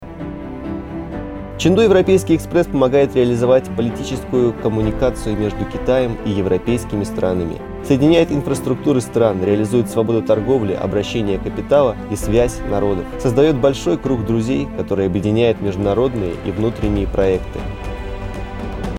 俄语样音试听下载